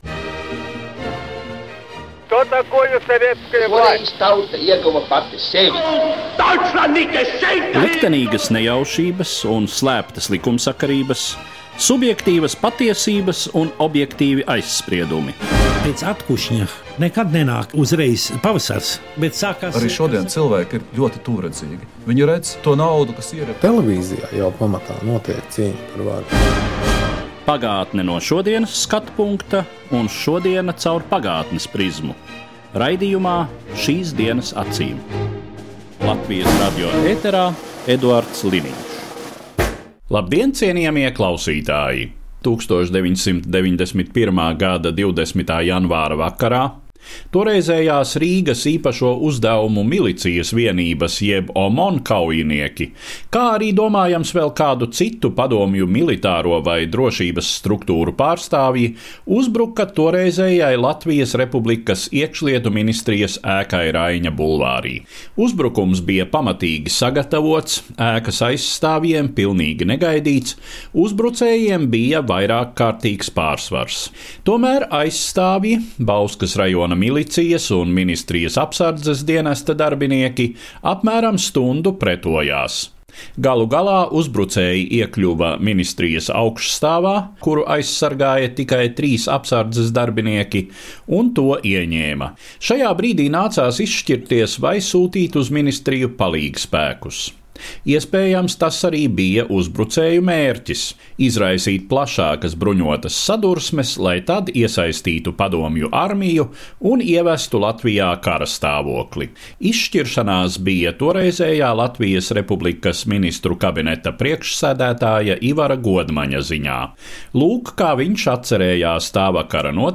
OMON uzbrukums 1990. gada 20. janvārī. Saruna